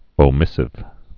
(ō-mĭsĭv)